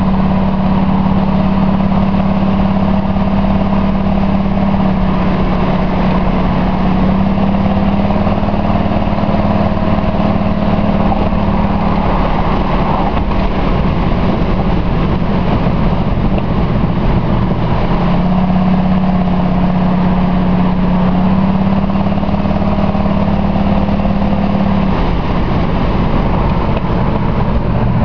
With no converter to pre-quiet things down and teeny weeny resonators, the sound is something extraordinary. Now that it has had a few miles to mellow out, it ranges from a mellow bark at slow cruising speeds to a roar at part-throttle acceleration to Total Armageddon at WOT (the window crank handles will rattle with the accelerator to the floor!).
Gentle acceleration from a complete stop in a residential area